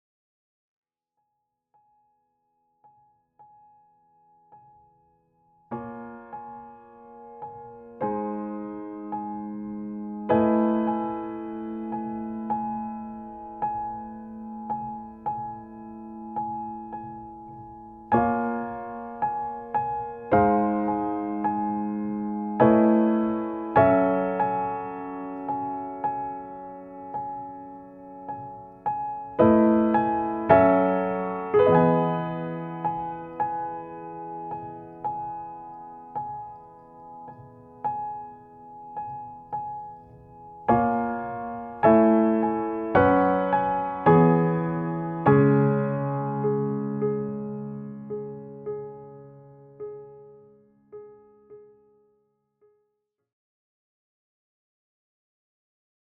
Klavier & Kompositionen
Stimme
Posaune
Schlagzeug